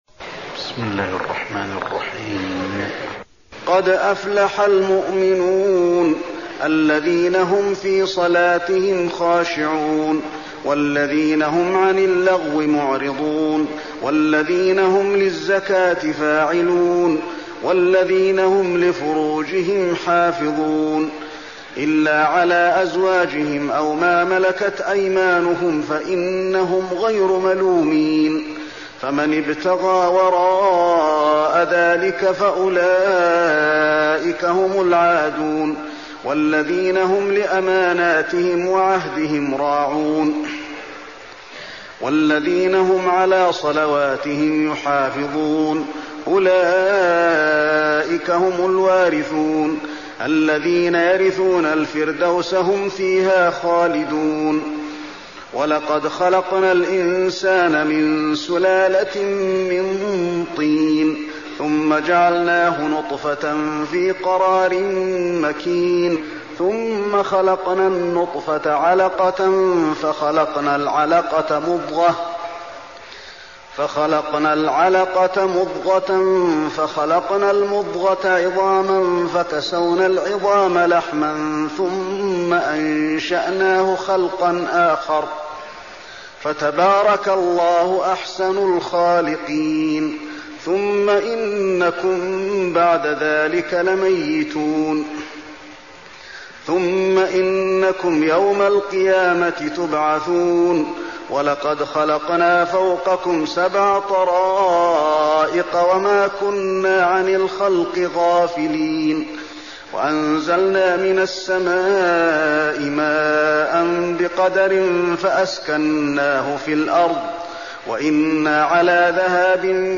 المكان: المسجد النبوي المؤمنون